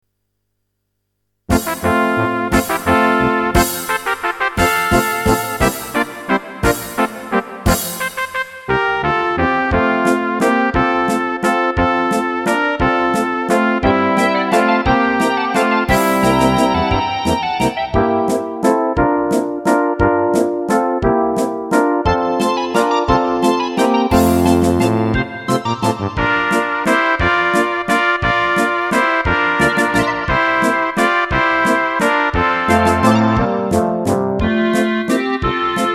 Rubrika: Národní, lidové, dechovka
- valčík
Karaoke